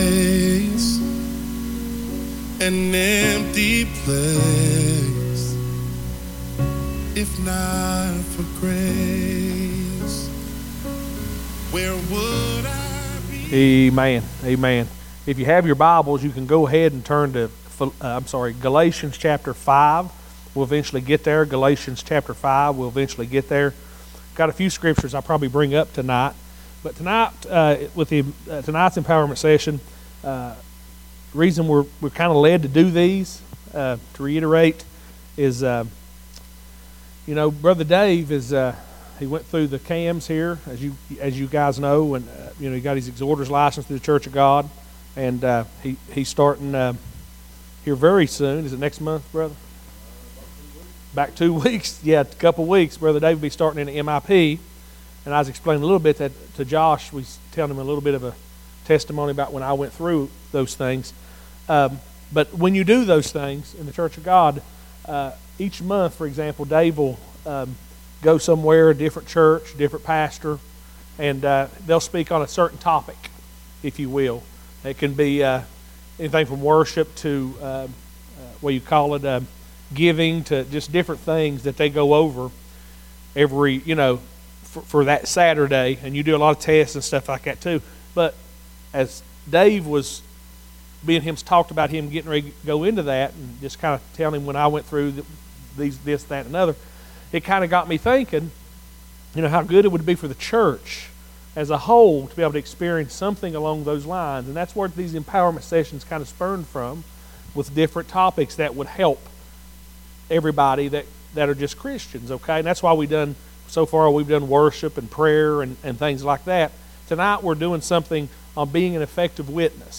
Wednesday Summer Empowerment Sessions